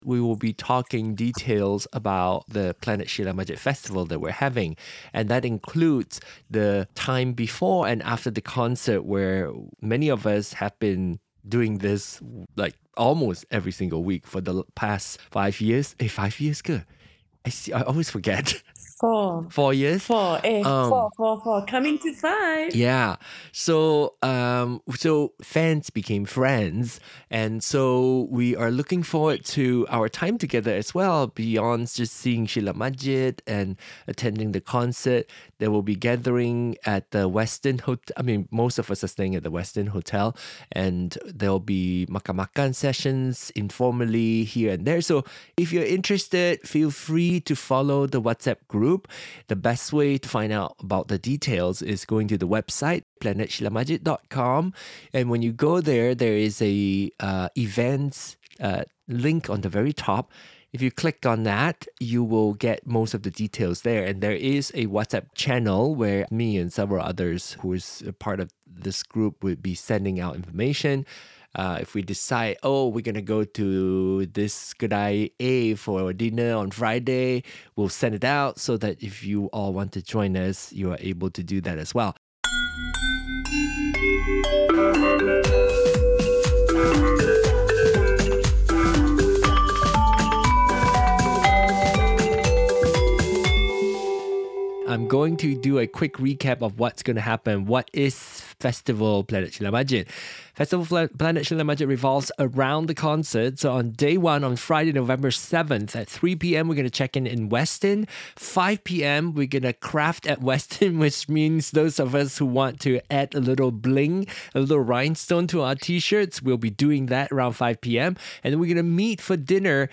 Interviews and highlights of conversations from our weekly virtual Planet Sheila Majid 🪐 fan club gatherings.